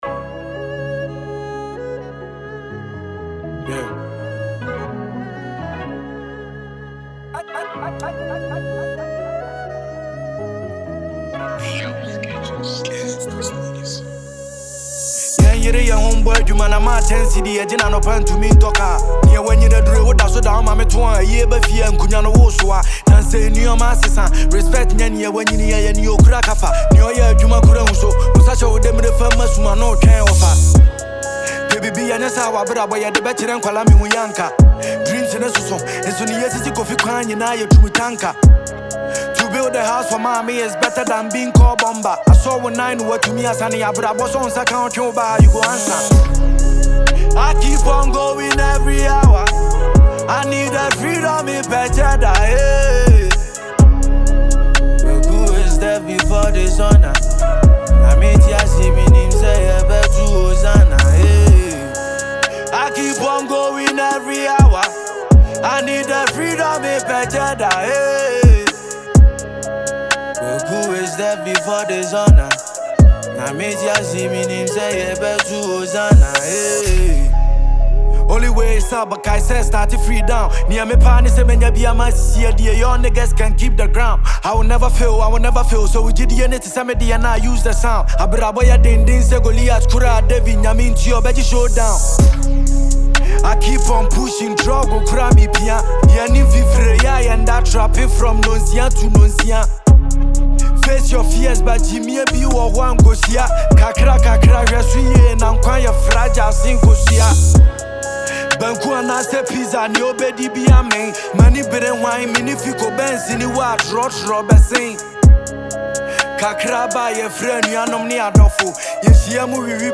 a Ghanaian rapper